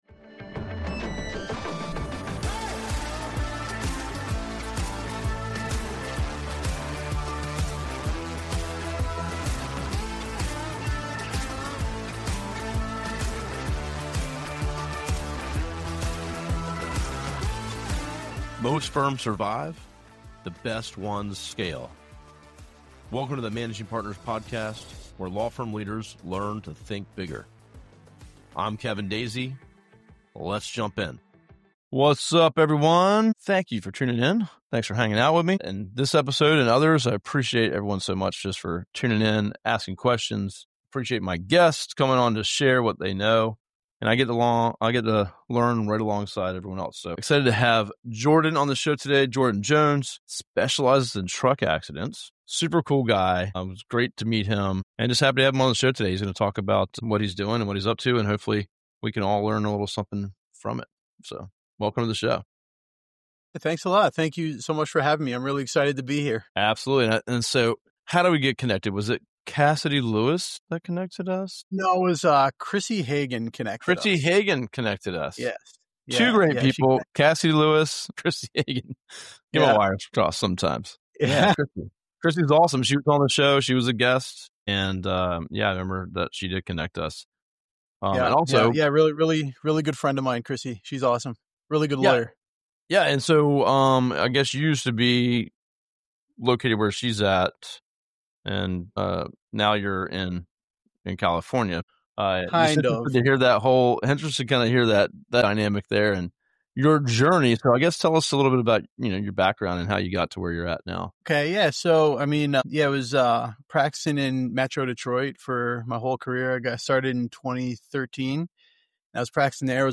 They explore why trucking cases demand a different mindset, how law firm owners can avoid scaling traps, and why speaking and teaching outperform selling when it comes to long-term growth. This conversation is packed with real-world insights for managing partners navigating early-stage growth, staffing challenges, and operational maturity.